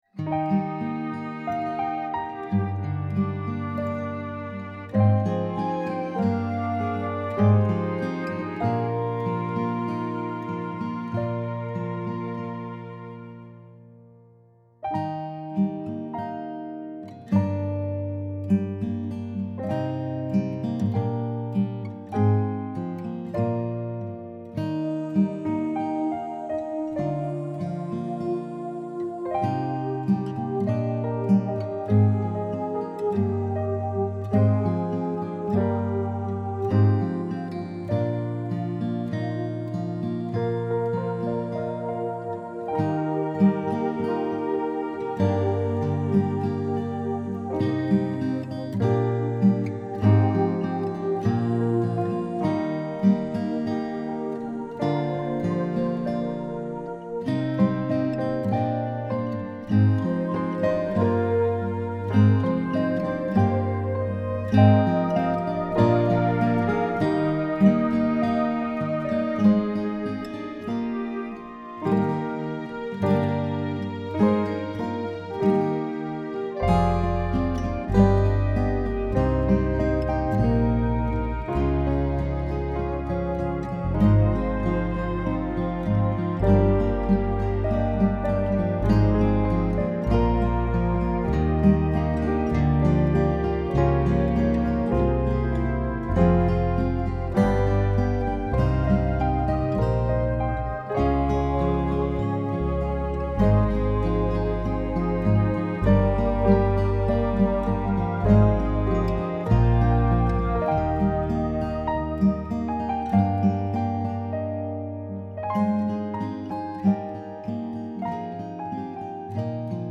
Arrangement Mix
the-door-arrangement-10-24.mp3